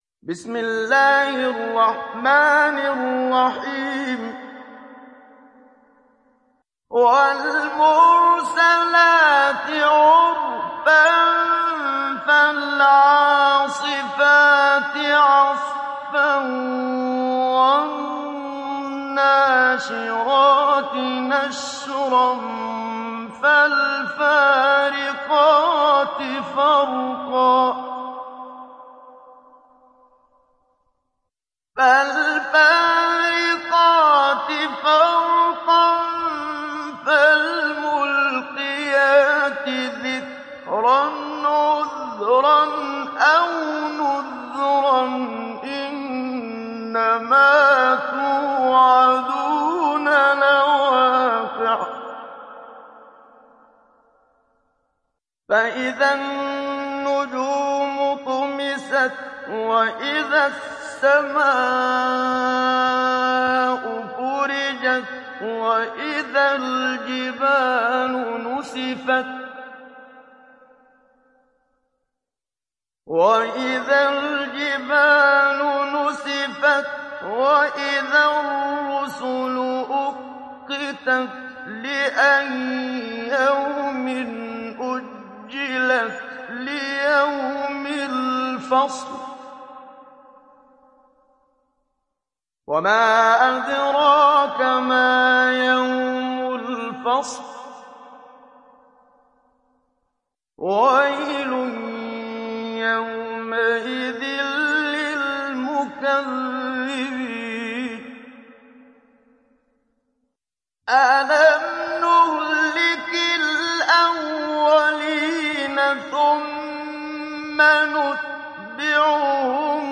Sourate Al Mursalat Télécharger mp3 Muhammad Siddiq Minshawi Mujawwad Riwayat Hafs an Assim, Téléchargez le Coran et écoutez les liens directs complets mp3
Télécharger Sourate Al Mursalat Muhammad Siddiq Minshawi Mujawwad